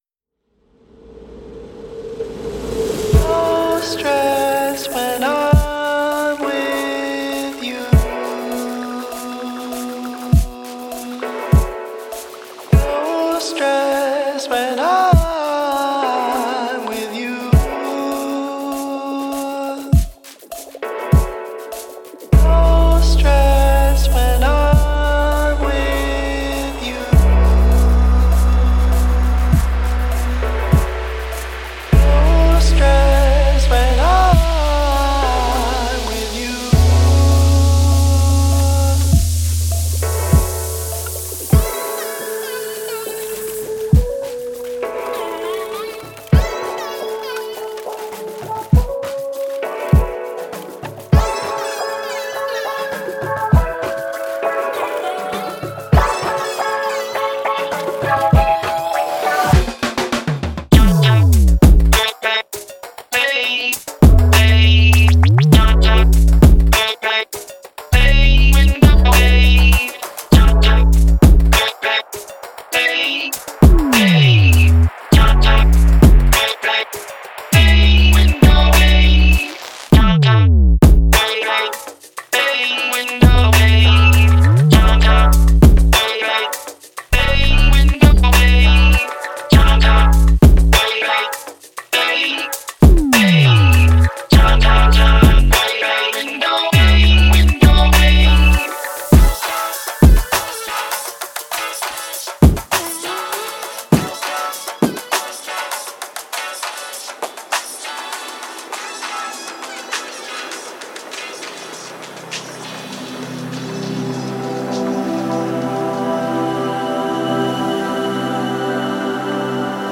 详细地讲，我们希望找到1.95GB的原始内容，其中包括542个弯弯循环，样本范围从100bpm至125bpm。
现代，电子和魔术这些仅是描述此令人惊叹的内容的几句话。
24 Bit Quality